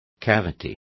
Also find out how fosa is pronounced correctly.